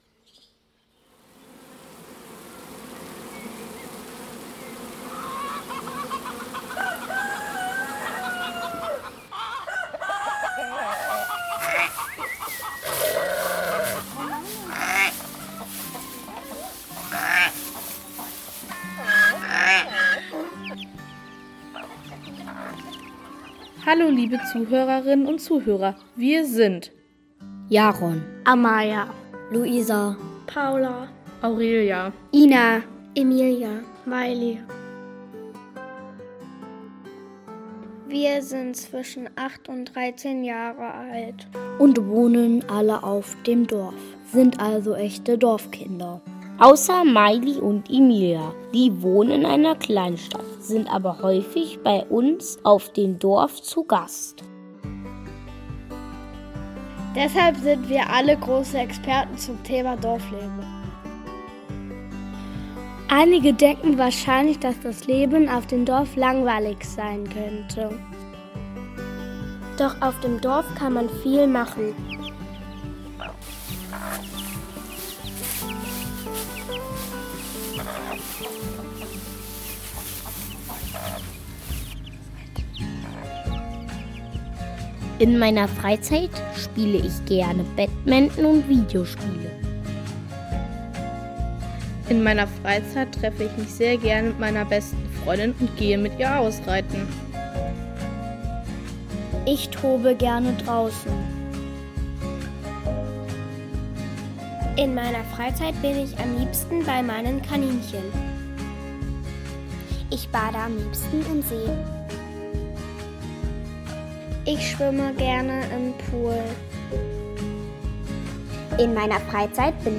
Ein Hörspiel aufnehmen, dass unser gemeinsames Leben auf dem Dorf akustisch darstellt.
Mit Interviews. Mit typischen Geräuschen und einer interessanten Rahmen-Geschichte über das Leben in einem kleinen Dorf. Ein anschaulicher Vergleich zwischen früher und heute.